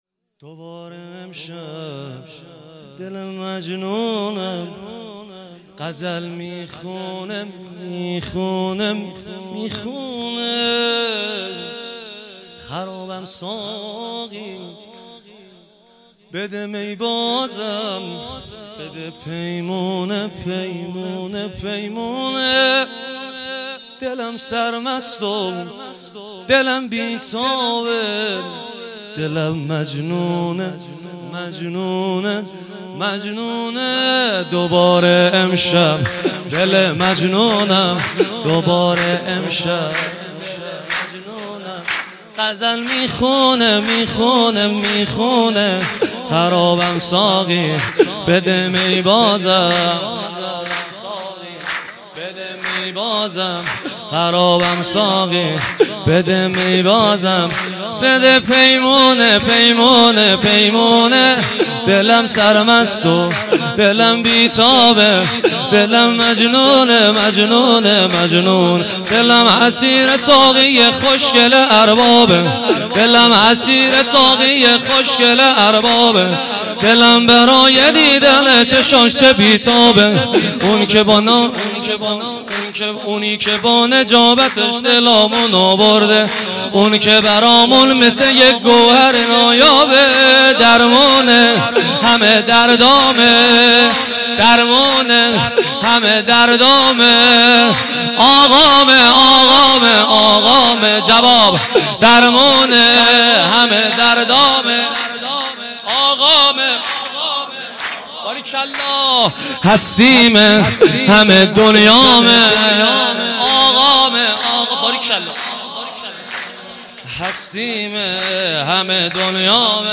سرود ا دوباره امشب دل
عیدانه سرداران کربلا | شب دوم